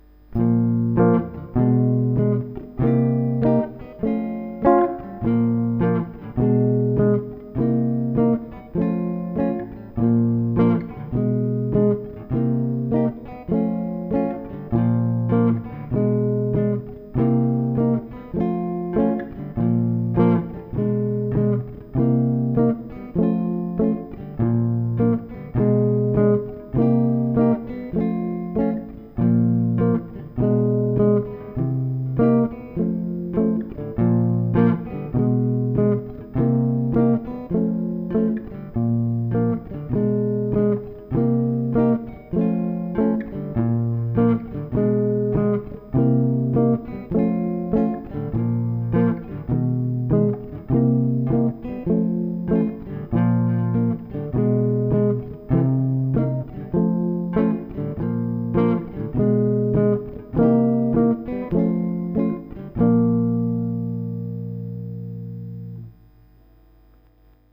Un playback de la tourne de deux mesures :
| Bb6/9 Bdim7 | Cm7 F#5 |
• Tempo 100 :